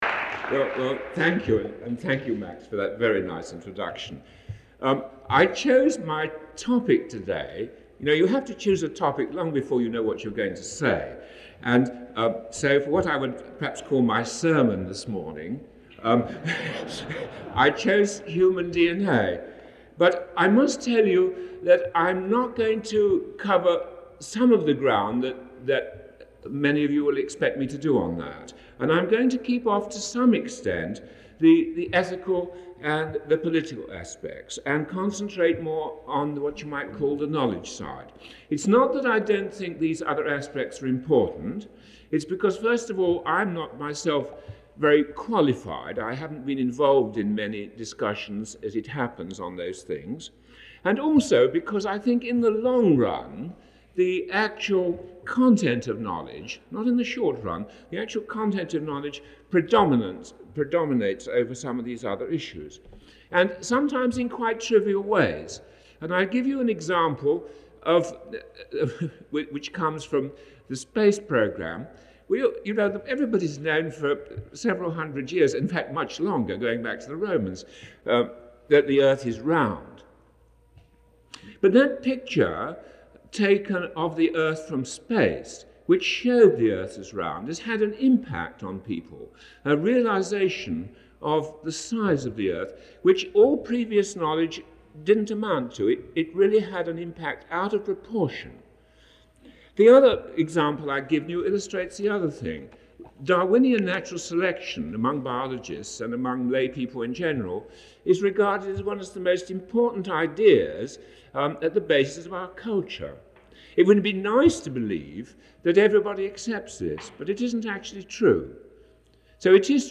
Francis Crick Lecture